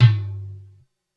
Index of /90_sSampleCDs/300 Drum Machines/Korg DSS-1/Drums01/06
LoTom.wav